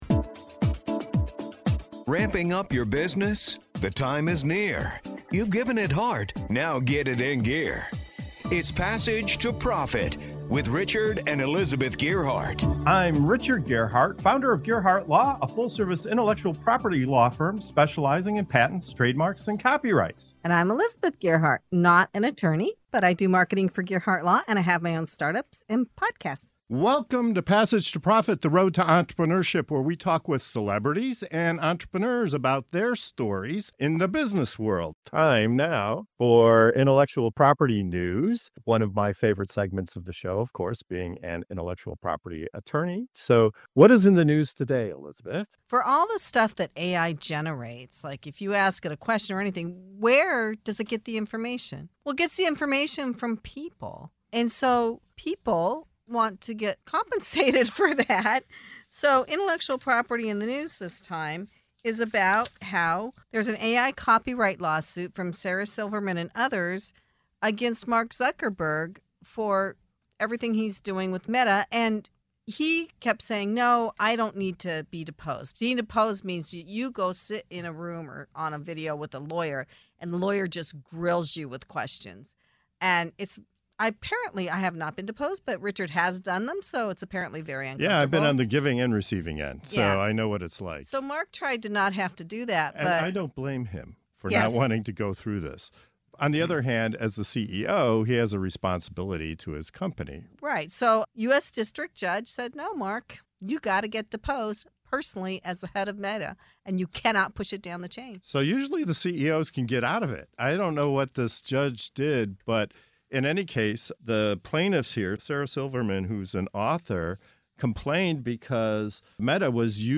Our hosts break down what this means for creators, the ethical implications of AI, and why protecting your ideas has never been more important. Tune in for a lively discussion on the future of intellectual property in the age of AI!